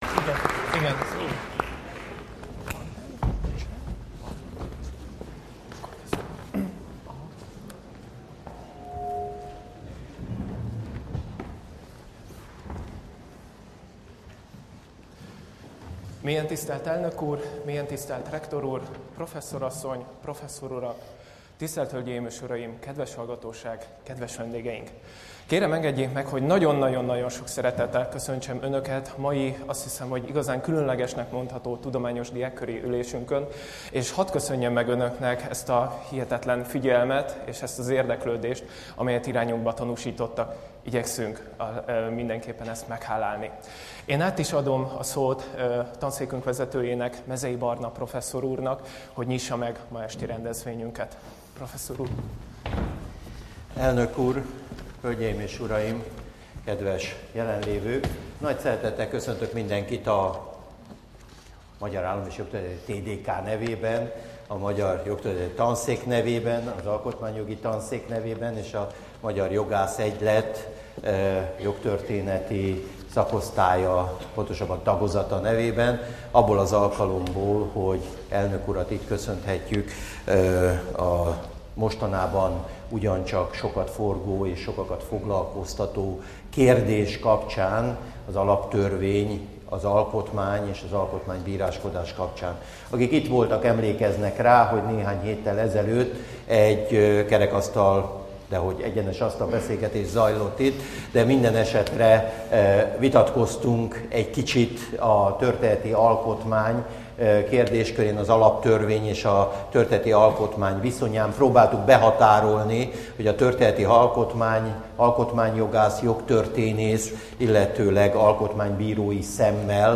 Nagy sikerrel zajlott le a Tudományos Diákkör ülése 2012. március 21-én, amikor Sólyom László el�adását hallgathatták meg az érdekl�d�k. Az el�adás teljes egészében letölthet� honlapunkról az alábbi linkr�l (a file mérete körülbelül 80 megabyte) (2012. március 25.)